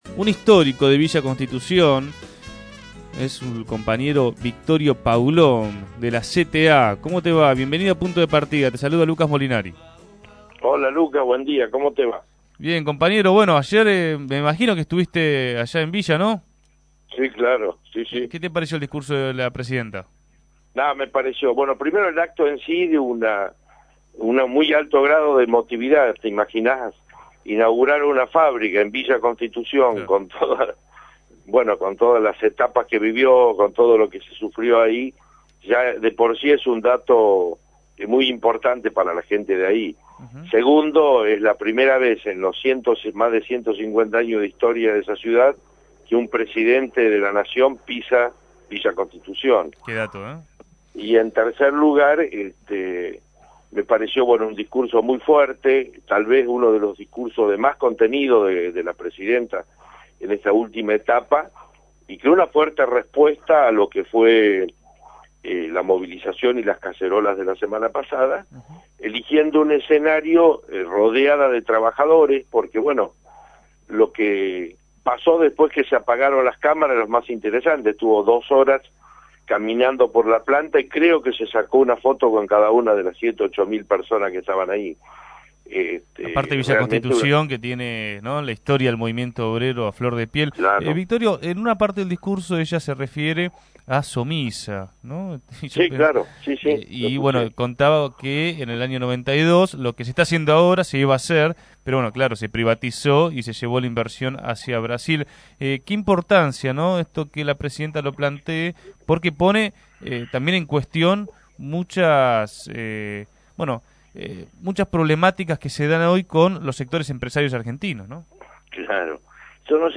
En diálogo con